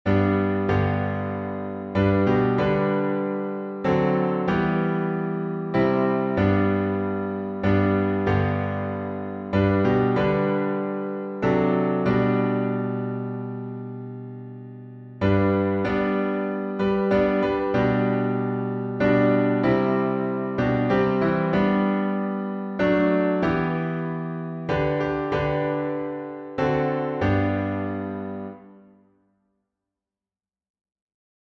Amazing Grace — six stanzas, alternate chording.